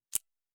通用点击.wav